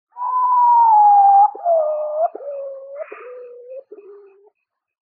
Play Potoo Ahoo Hoo Hoo Hoo 1 - SoundBoardGuy
Play, download and share Potoo Ahoo hoo hoo hoo 1 original sound button!!!!
potoo-a-hoo-hoo-hoo-hoo-1.mp3